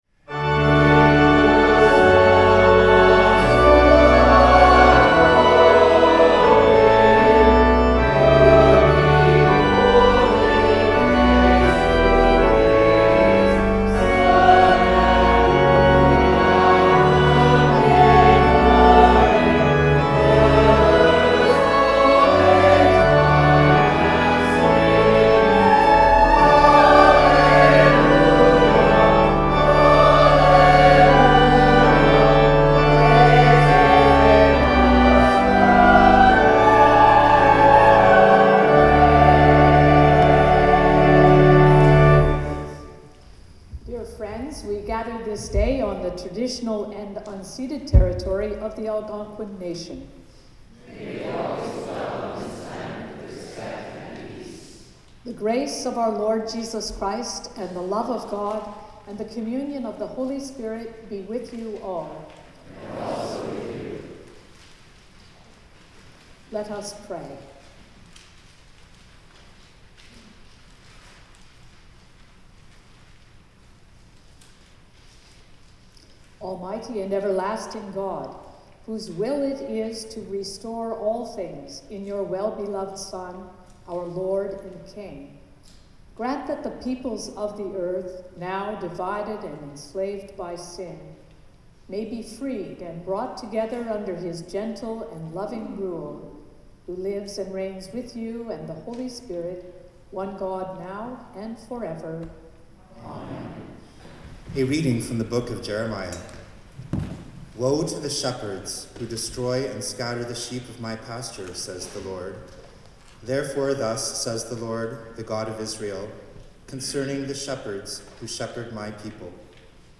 Sermons | St John the Evangelist
Second Reading: Colossians 1:11-20 (reading in French)
The Lord’s Prayer (sung)